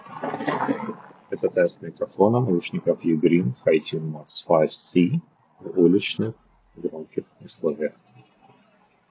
Микрофон
Запись голоса мне понравилась, по этому можно смело брать их и для долгих телефонных разговоров, вас точно услышат и разберут, что вы говорите, как на шумной улице, так и в тихом помещении.
В шумных условиях: